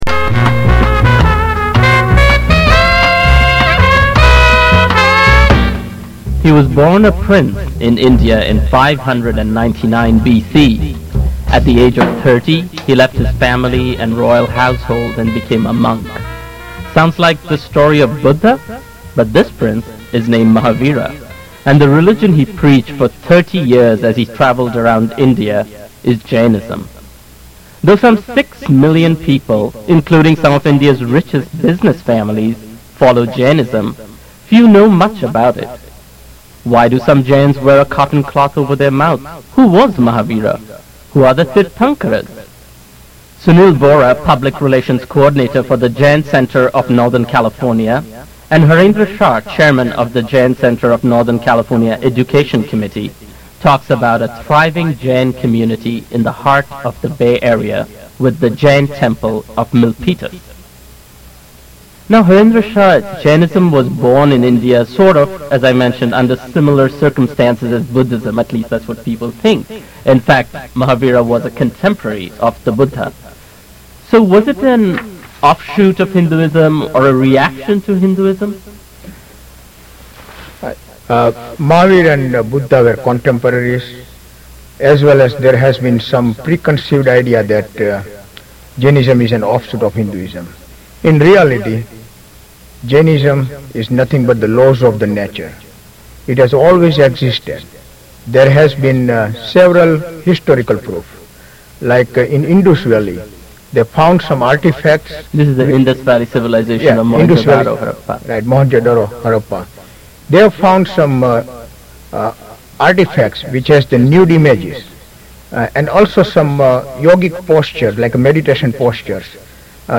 This an unedited interview audio as it was played on KALW 91.7 PBS Radio Station of SF. One may find this interview as an educational interview relating to Lord Mahavira and His Teachings.